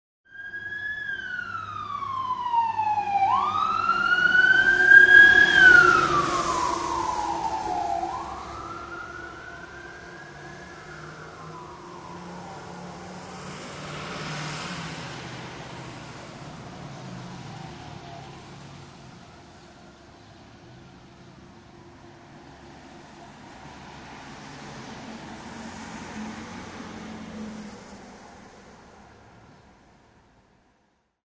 Звук сирены проезжающей пожарной машины